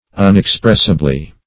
-- Un`ex*press"i*bly , adv.